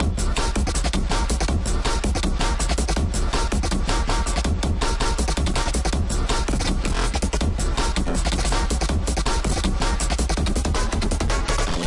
描述：缩短了简单的鼓循环
标签： 桶环 加工
声道立体声